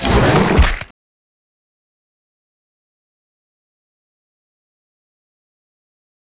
vpunch1.wav